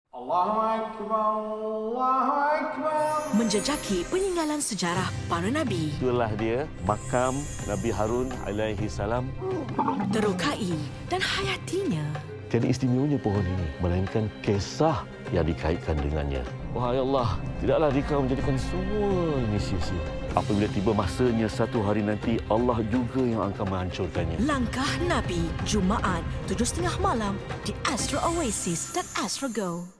Female
FIFA Announcer (Malay)
Langkah Nabi Documentary